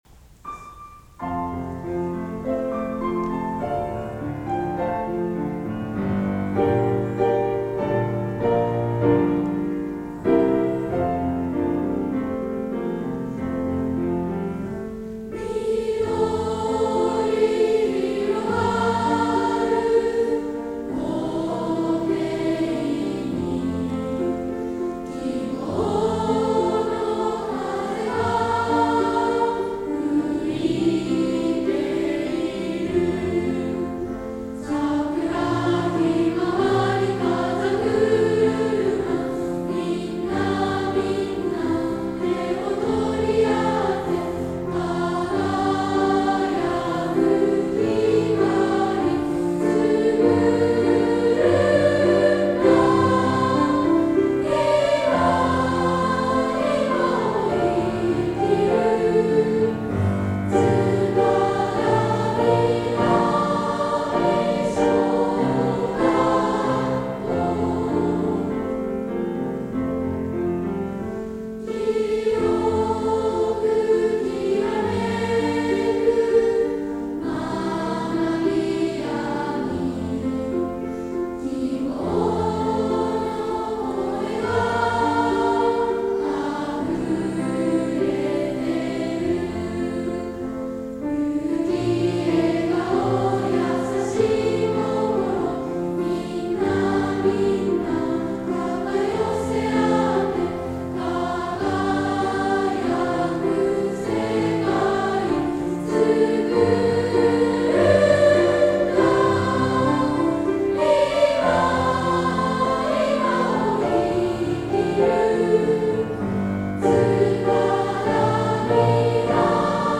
校歌合唱音源
校歌【合唱】